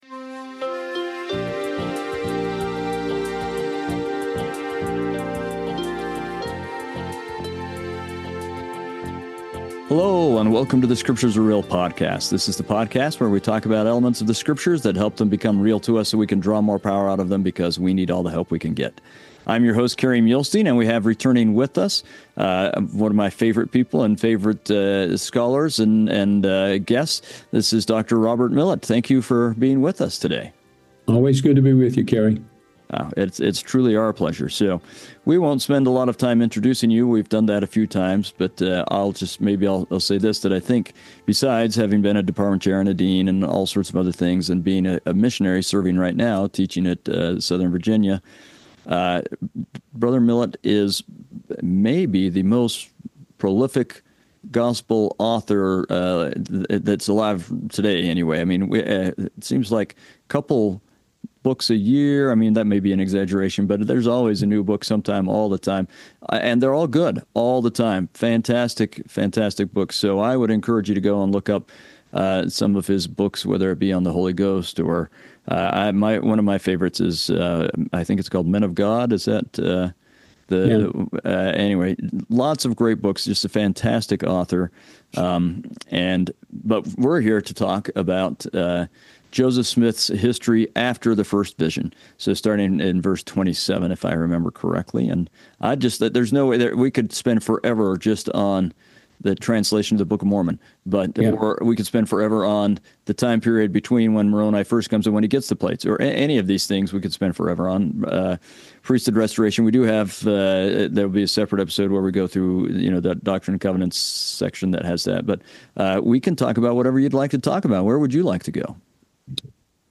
We interview both experts (people with language, archaeological, historical backgrounds, etc.), and lay folks, and explore times when the scriptures became real to them. This is done from the viewpoint of members of the Church of Jesus Christ of Latter-day Saints.